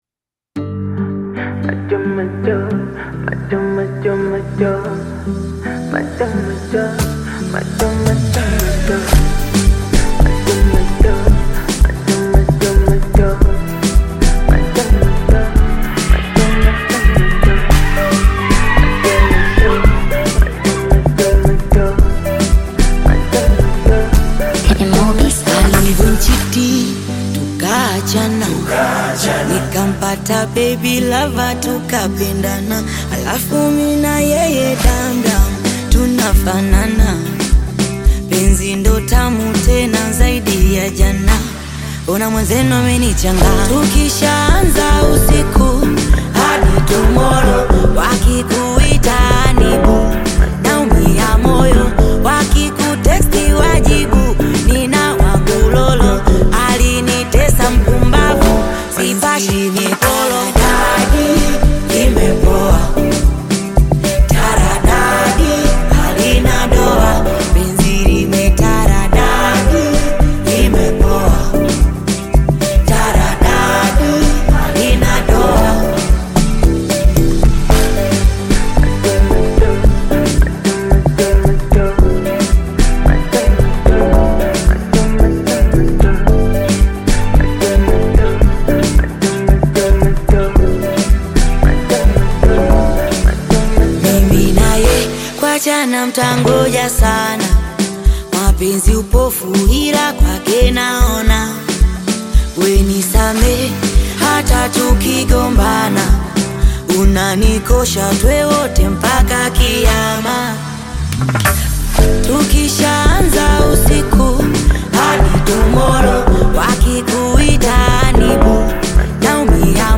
Tanzanian Bongo Flava